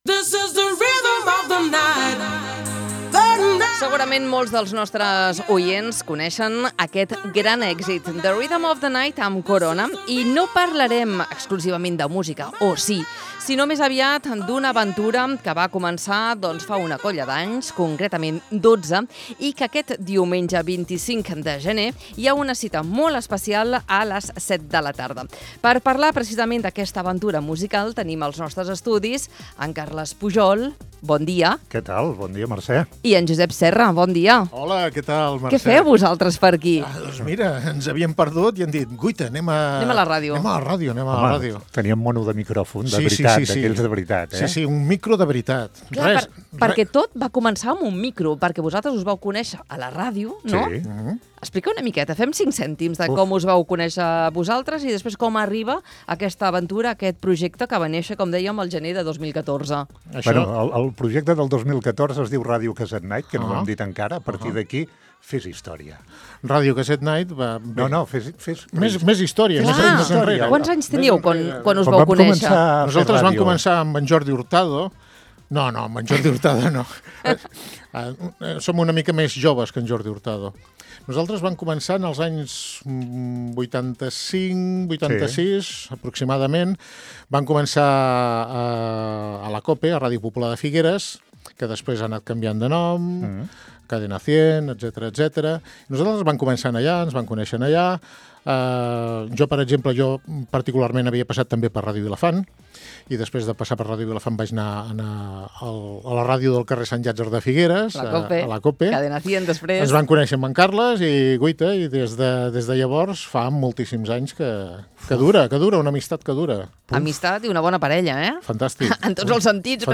LVDM - ENTREVISTA - RADIO CASSETTE NIGHT 22 GENER 26~0.mp3